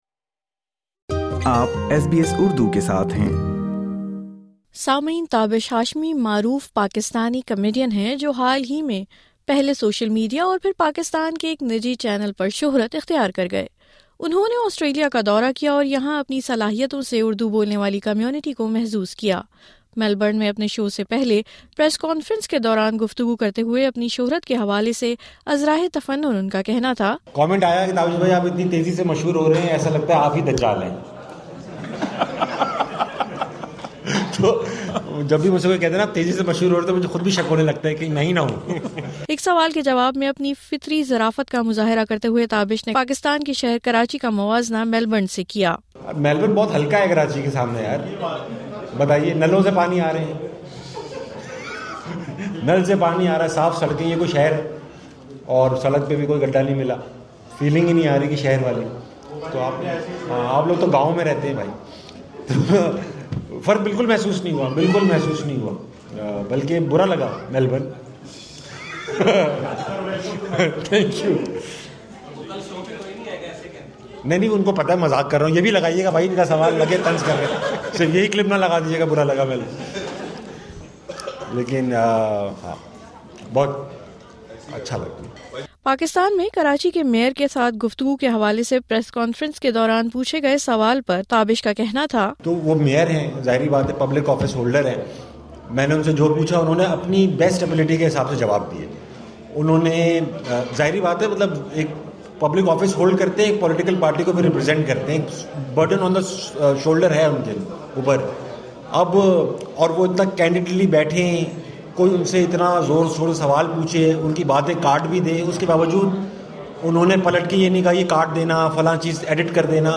In a friendly chat with SBS, Tabish Hashmi, a Canadian citizen, shared his thoughts on the similarities he’s noticed between Australia and Canada. He even mentioned how some of the roads in Australia remind him of those back in Canada.